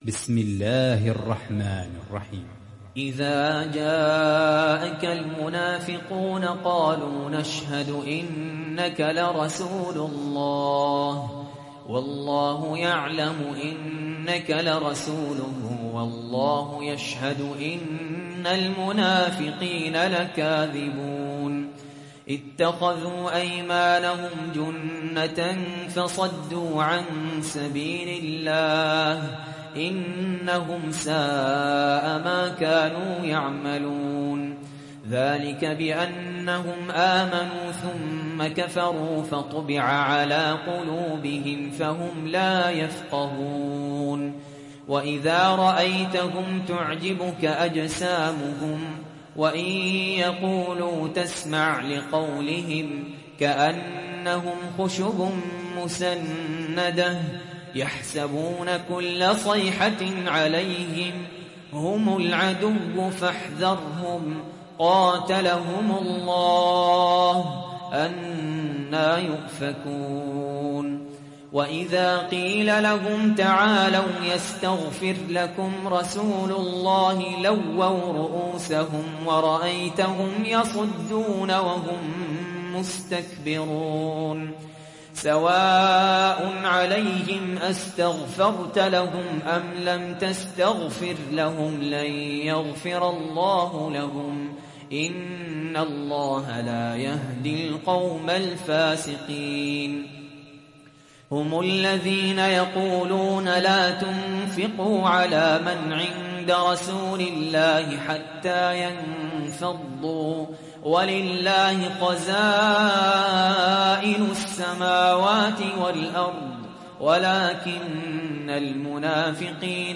(رواية حفص)